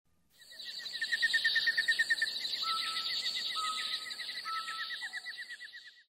PAJAROS Y DESIERTOSONIDO DE PAJAROS Y DESIERTO
Ambient sound effects
pajaros_y_desiertosonido_de_pajaros_y_desierto.mp3